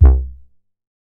MoogBrassA.WAV